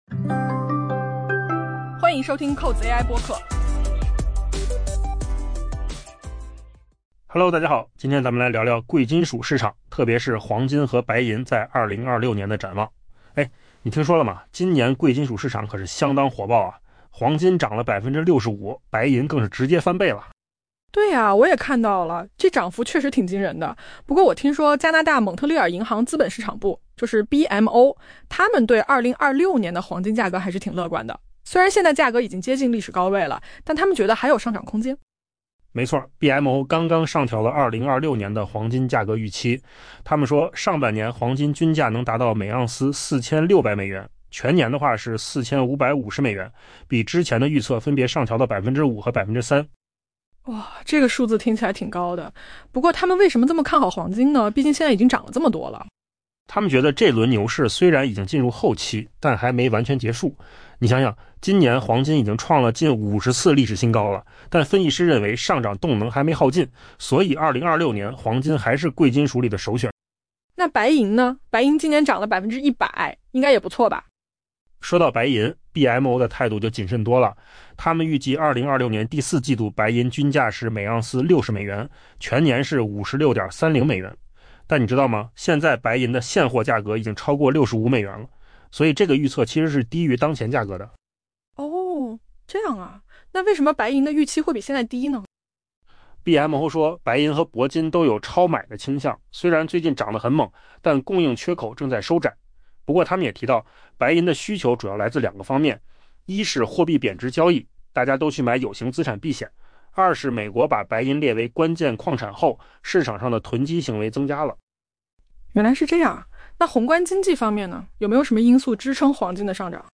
AI 播客：换个方式听新闻 下载 mp3 音频由扣子空间生成 贵金属市场迎来了非凡的一年——黄金价格上涨超 65%，白银价格涨幅更是突破 100%。